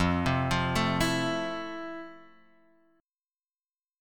F Major 7th